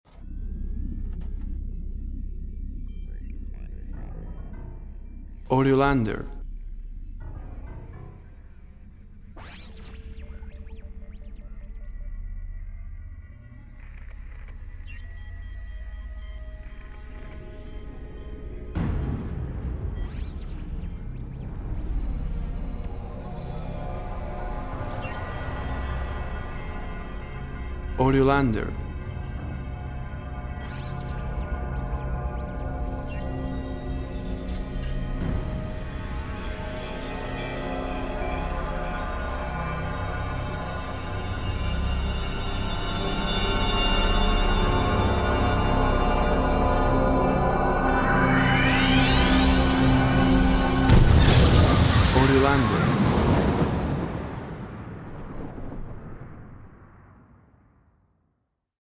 An atmospheric piece with space-type soundscape.